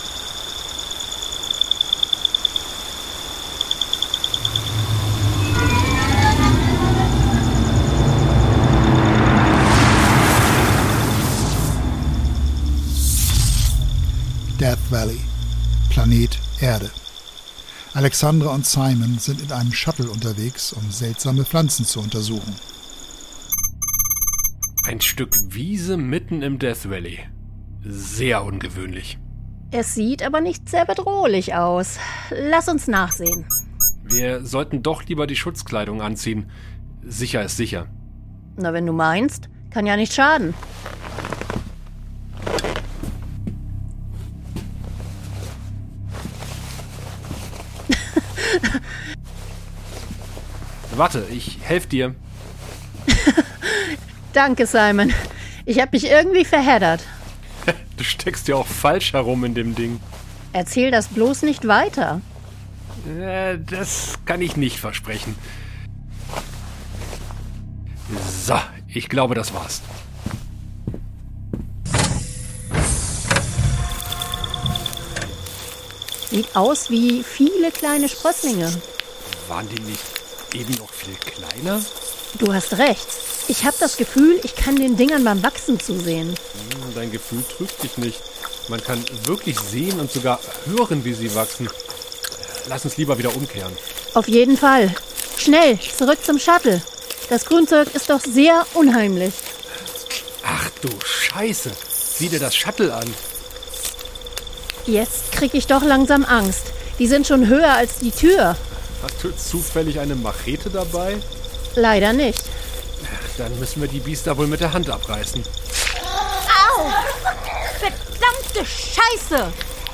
Das Hörspiel der Podcaster